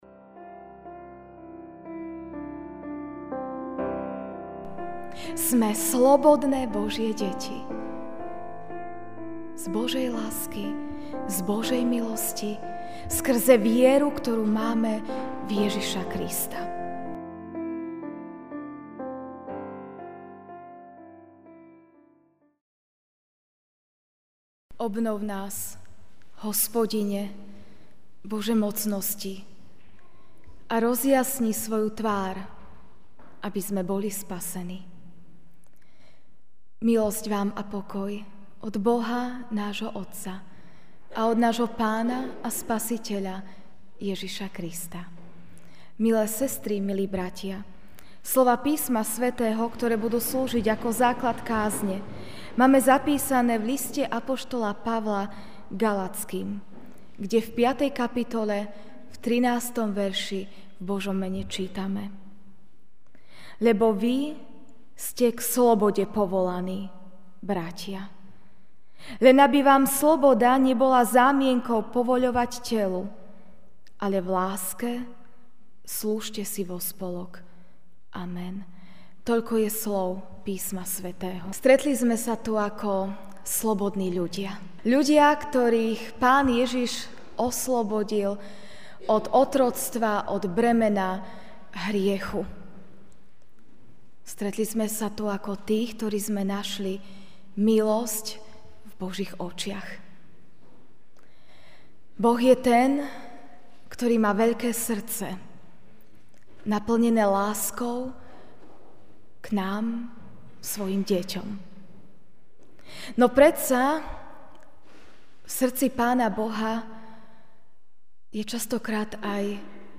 okt 08, 2017 Povolaní k slobode MP3 SUBSCRIBE on iTunes(Podcast) Notes Sermons in this Series Ranná kázeň: Povolaní k slobode (Ga 5,13) Lebo vy ste k slobode povolaní, bratia.